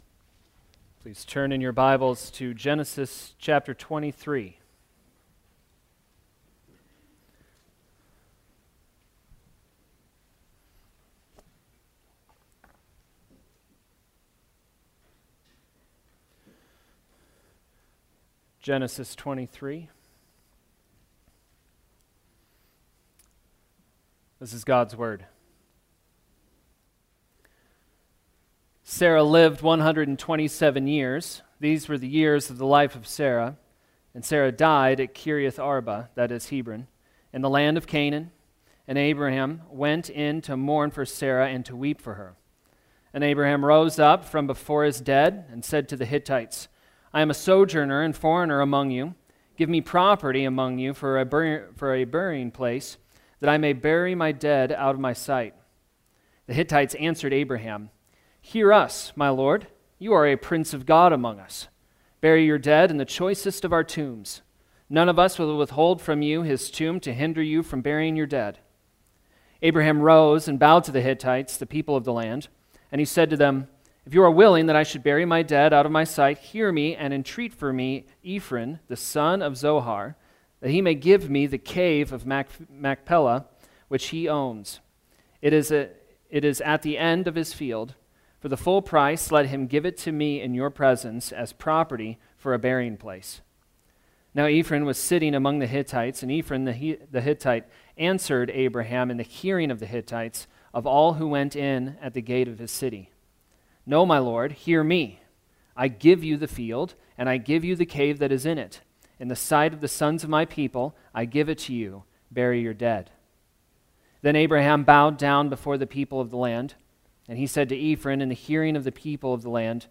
Genesis Sermons